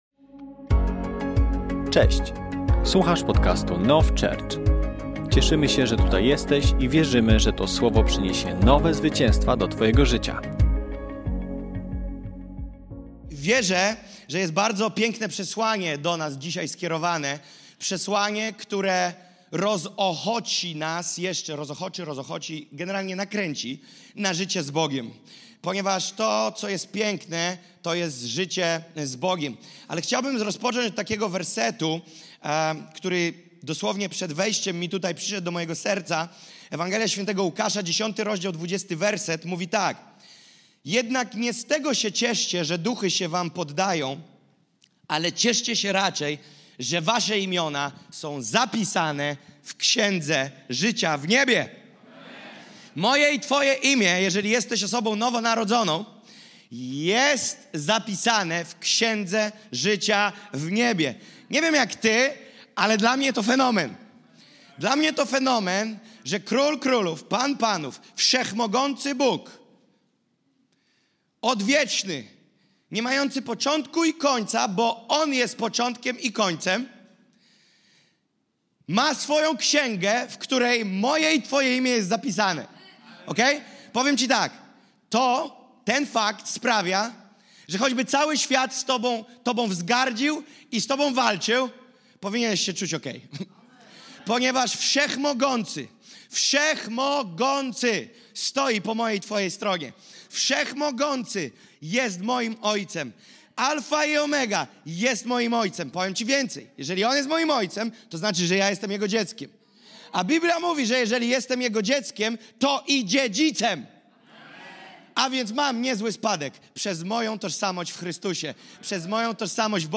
Kazanie zostało nagrane podczas niedzielnego nabożeństwa NOF Church 02.03.2025 r. Download episode Share Share Copy URL Subscribe on Podcast Addict